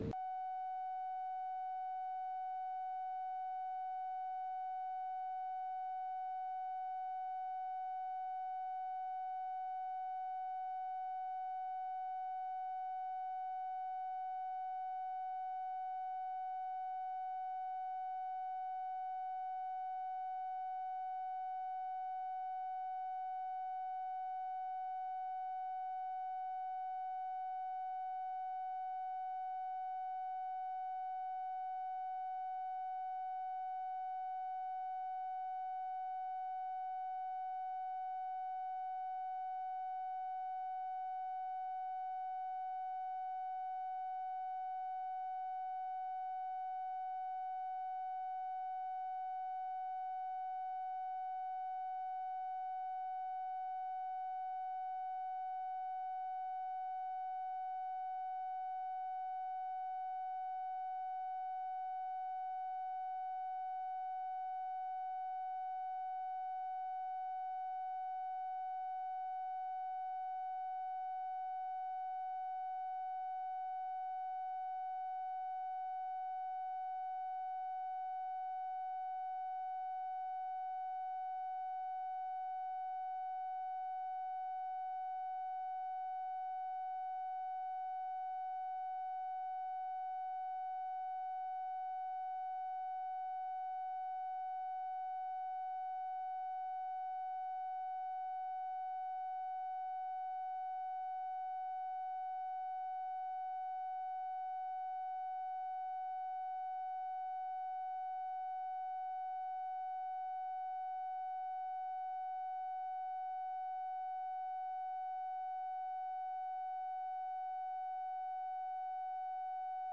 Conversation with LADY BIRD JOHNSON and JACK VALENTI, March 10, 1964
Secret White House Tapes